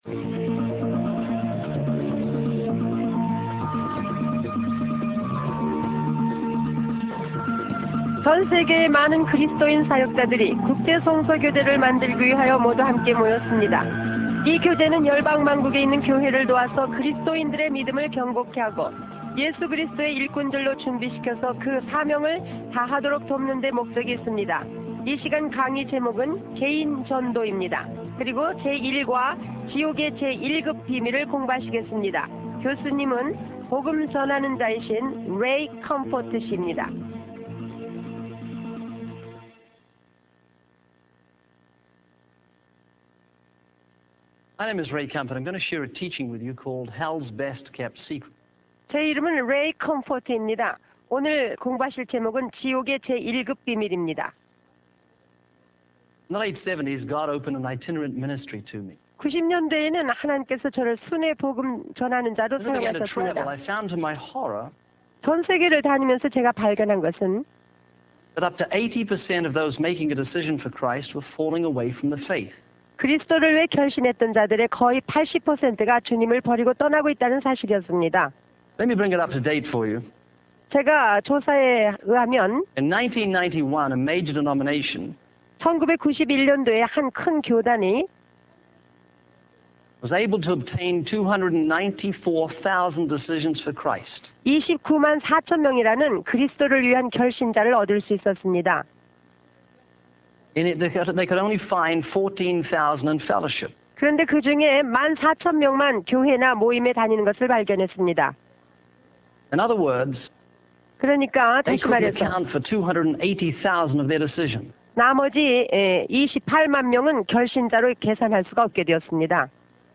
In this sermon, the preacher challenges the popular teaching that the Gospel is solely about finding peace, joy, love, and fulfillment in Jesus Christ. He uses a story of two men on a plane, where one is given a parachute and told it will improve his flight.